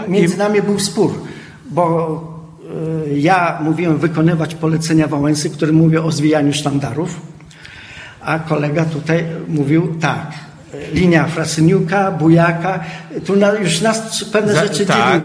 Ponieważ audycja prowadzona była na żywo z siedziby Muzeum Historycznego w Ełku, gdzie do 9 czerwca możecie zwiedzać wystawę „Wybory ’89 w Ełku”.